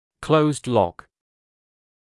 [kləuzd lɔk][клоузд лок]смещение диска ВНЧС без вправления, сопровождаемое ограниченным открытием рта, так как диск является механическим препятствием для перемещения мыщелков